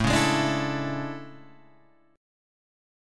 A Major 11th
AM11 chord {5 4 6 7 5 5} chord